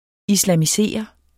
Udtale [ islaˈmiˈseˀʌ ]